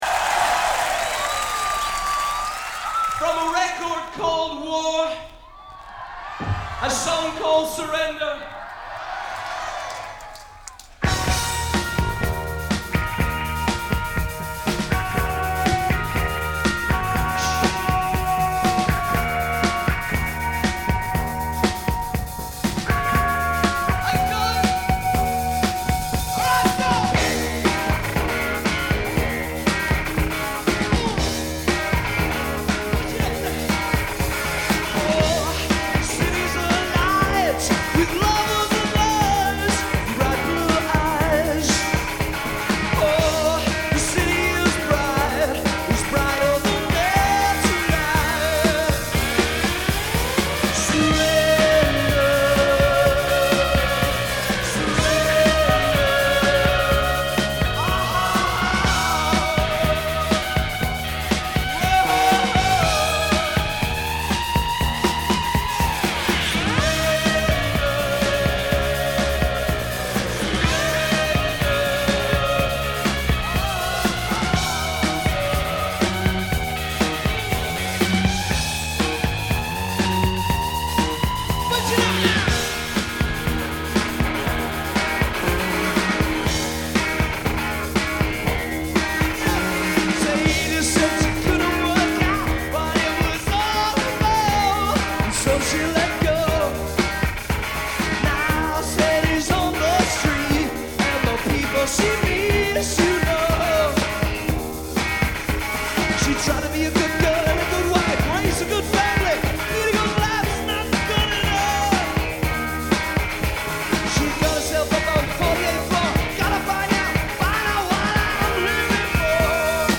Recorded live at the Hammersmith Palais on 12/6/1982